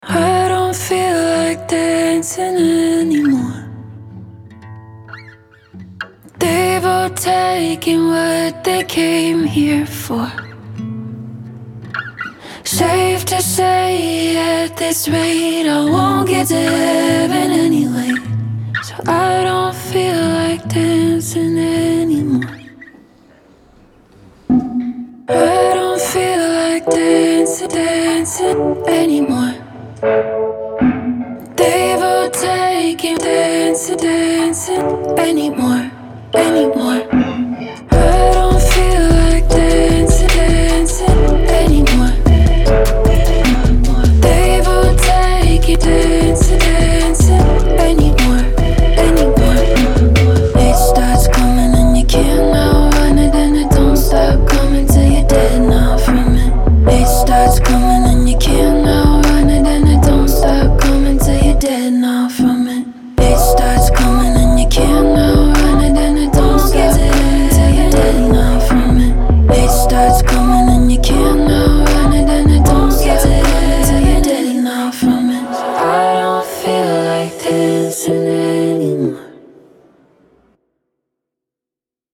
The tender vocals take front and center in this track